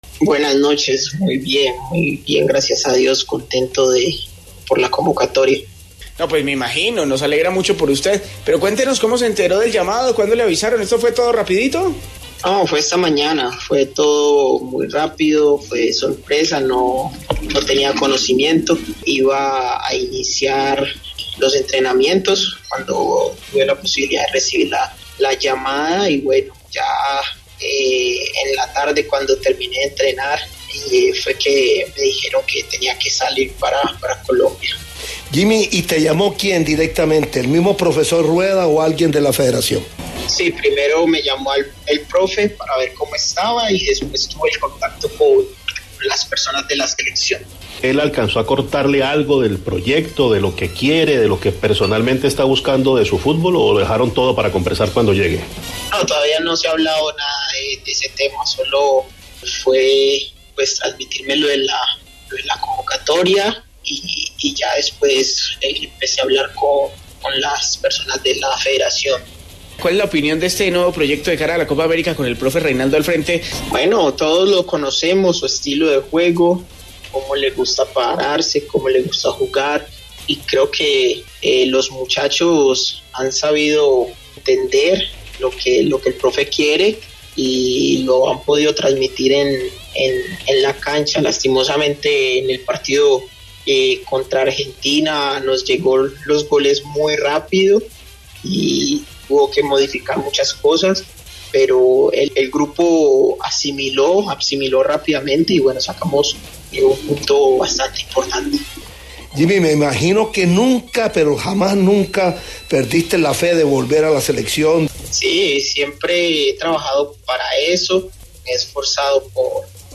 Venía trabajando para eso, de estar cerca a la Selección y esperemos que las cosas sigan de buena manera”, dijo Chará en El Alargue de Caracol Radio.